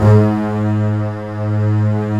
Index of /90_sSampleCDs/Roland L-CD702/VOL-1/STR_Cbs Arco/STR_Cbs1 f